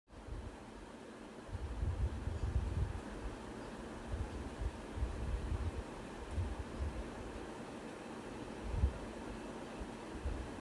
Download Fan sound effect for free.
Fan